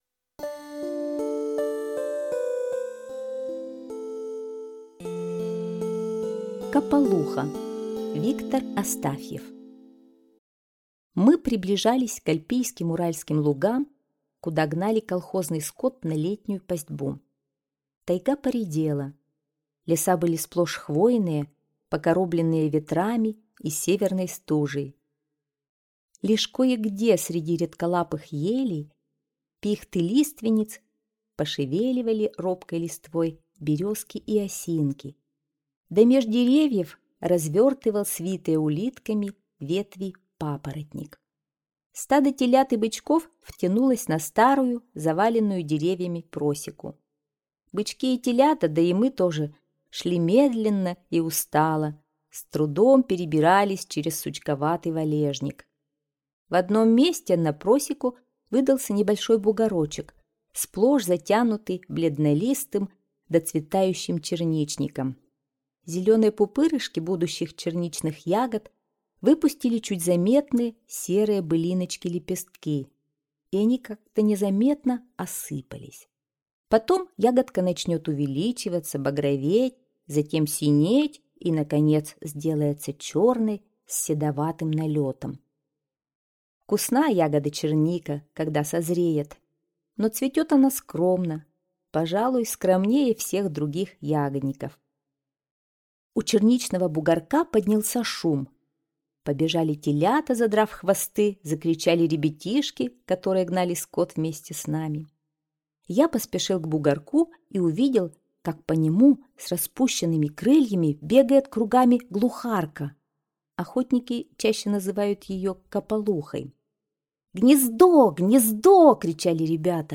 Аудиорассказ «Капалуха»